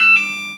beep_05.wav